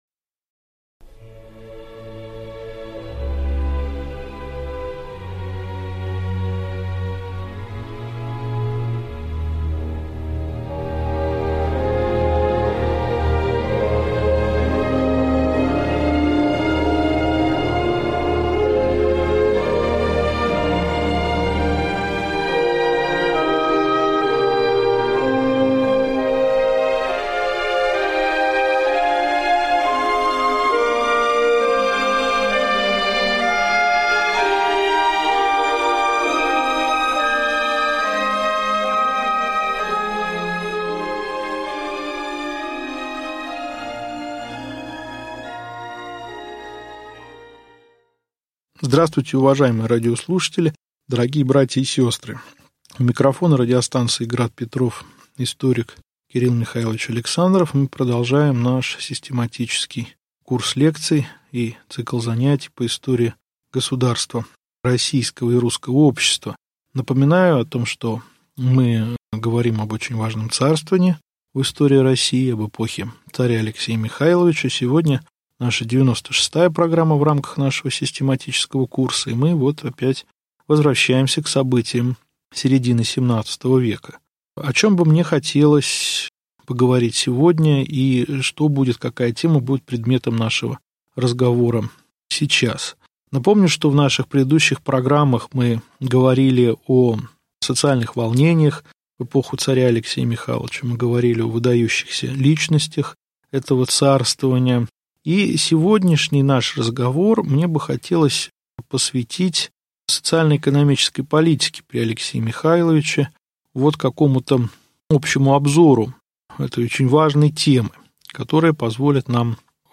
Систематический курс лекций по русской истории.